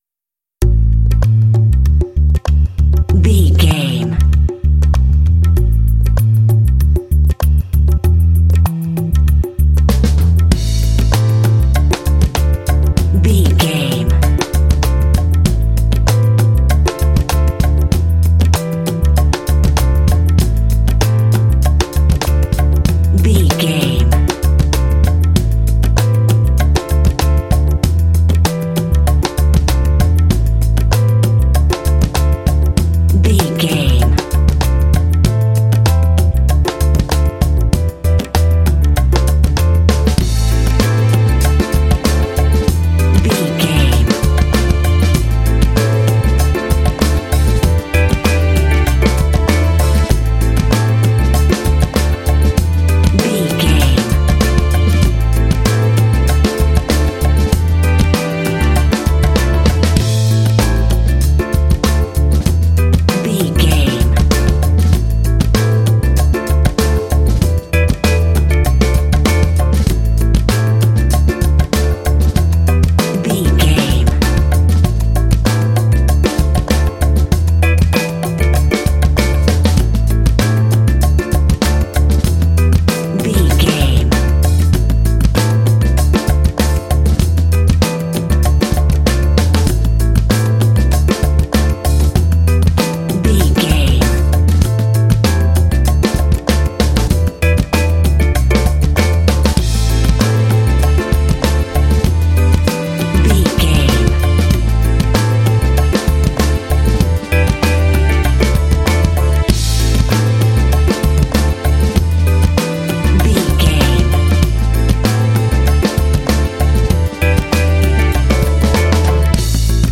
Uplifting
Aeolian/Minor
funky
smooth
bass guitar
percussion
drums
piano
strings
downtempo